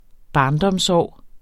Udtale [ ˈbɑːndʌms- ]